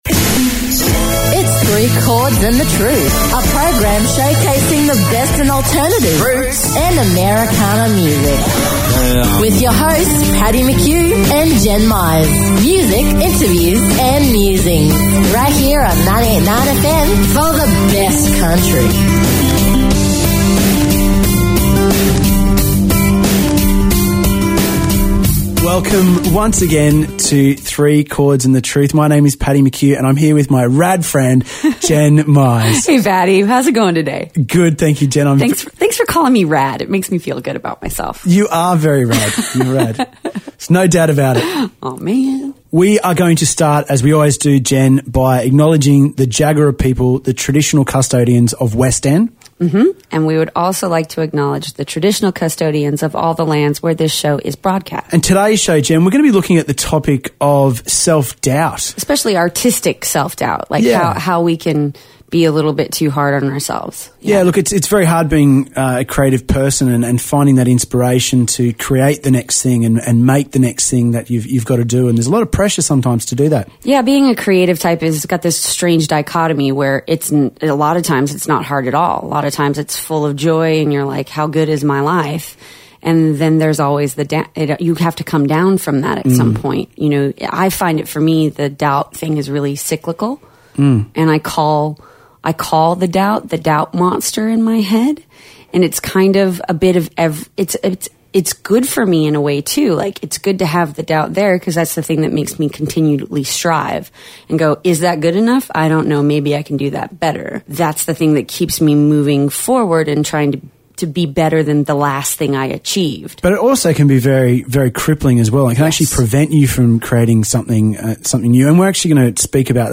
Music, interviews and musings every Thursday nights from 6pm and the repeat from 10am Sunday morning.